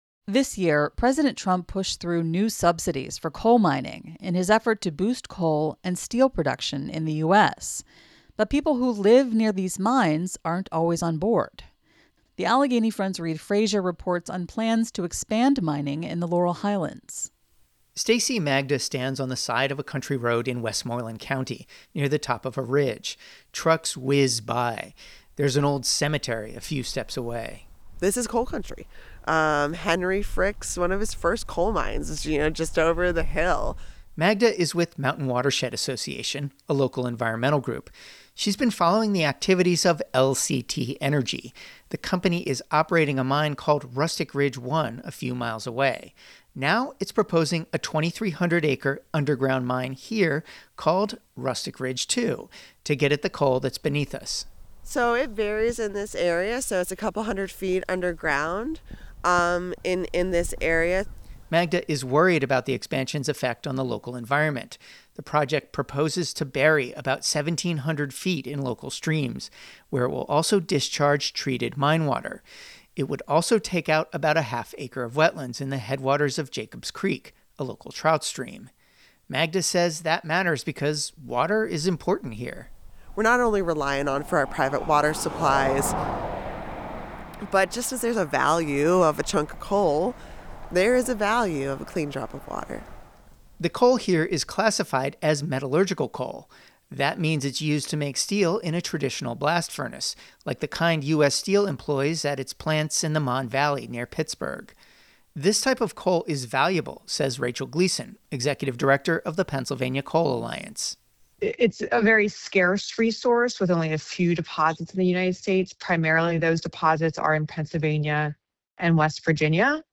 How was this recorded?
Trucks whiz by.